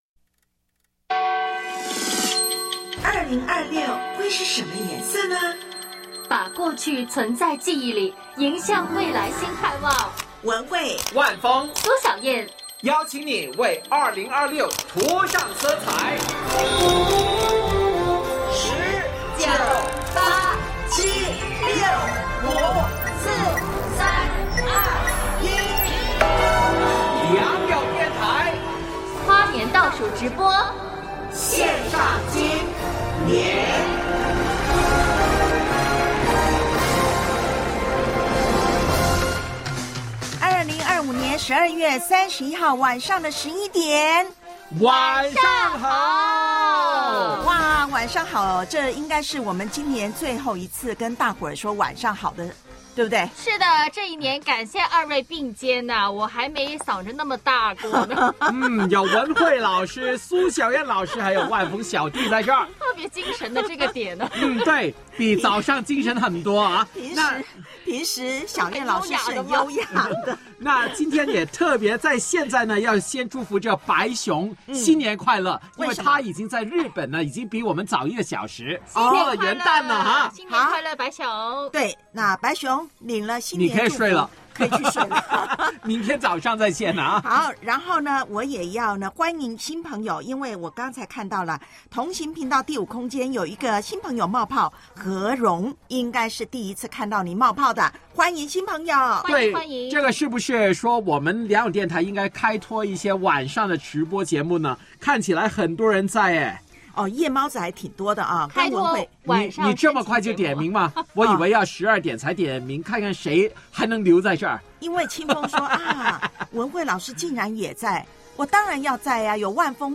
献上今年──2025-2026年跨年直播